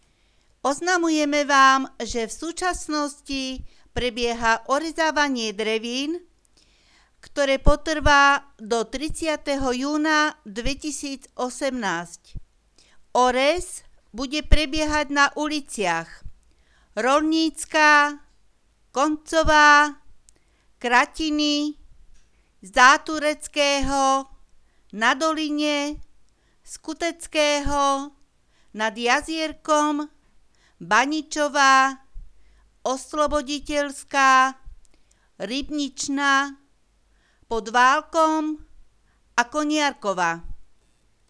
Hlásenia miestneho rozhlasu 5.4.2018 (Orez drevín - oznam)